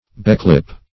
Search Result for " beclip" : The Collaborative International Dictionary of English v.0.48: Beclip \Be*clip"\, v. t. [imp.
beclip.mp3